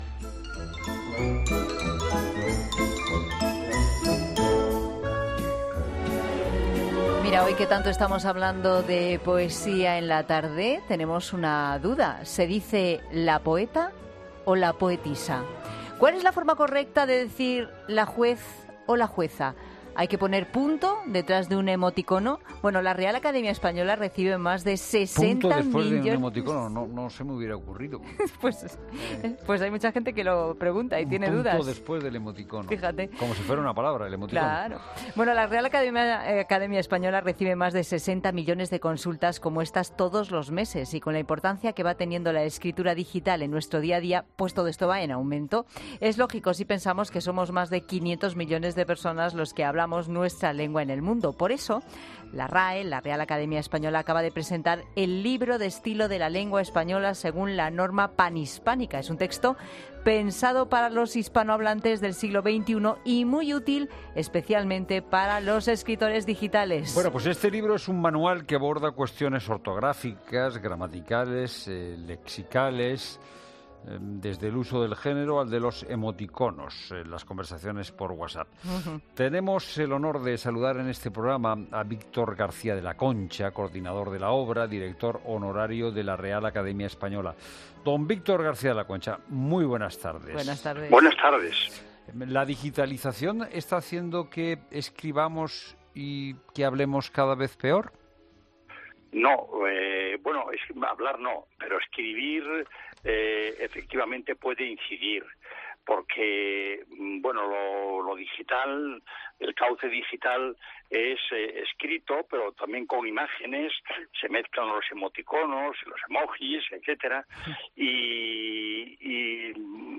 Víctor García de la Concha habla en 'La Tarde' del Libro de estilo de la lengua española